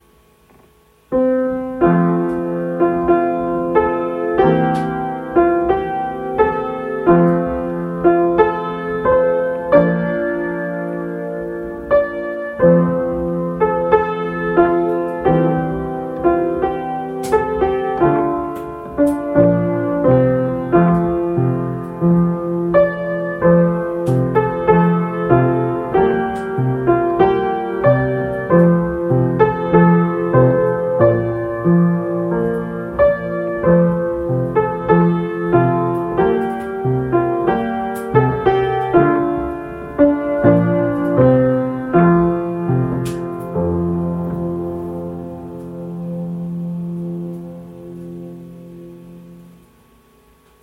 An easy arrangement for piano, in 3 keys